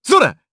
Kibera-Vox_Attack2_jp.wav